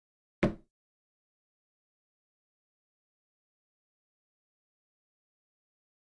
Cardiovascular Exercise; Single Foot On Wooden Floor.